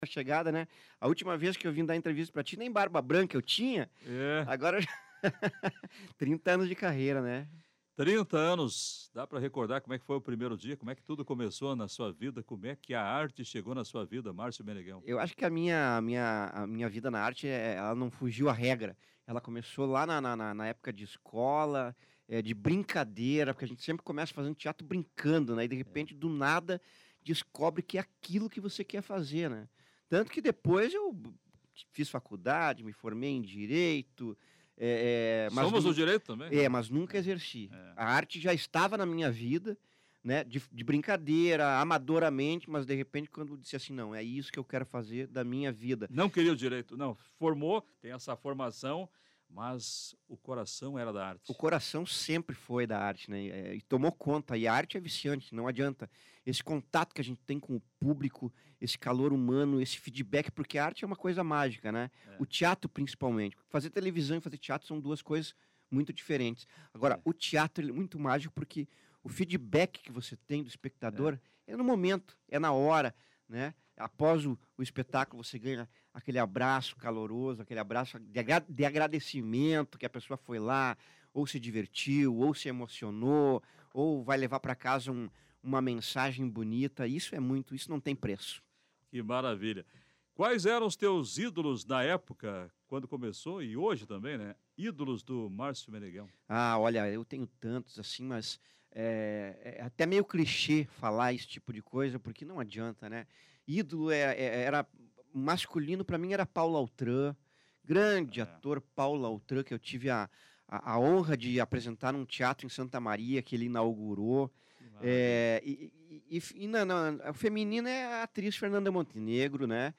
A trajetória foi contada no programa Comando Popular, da Rádio Planalto News (92.1).